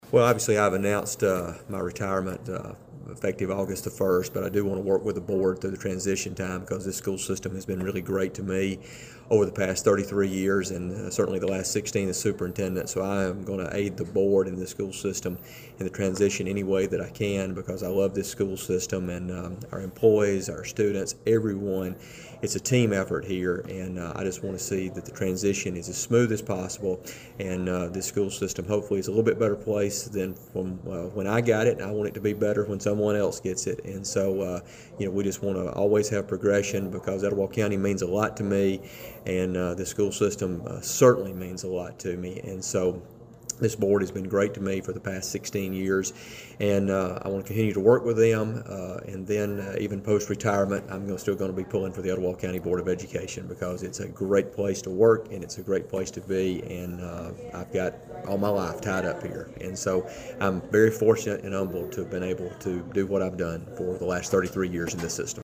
WEIS Radio spoke with Dr, Cosby following the approval, and he said:
Dr.-Cosby-Statement.mp3